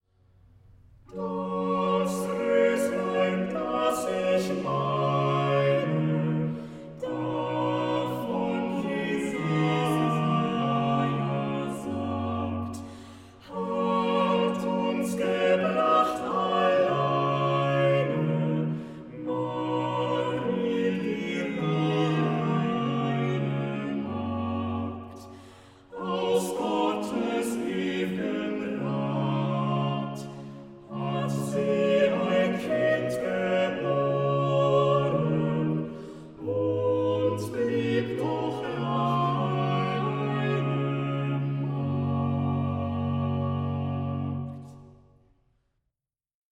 Instrumentalisten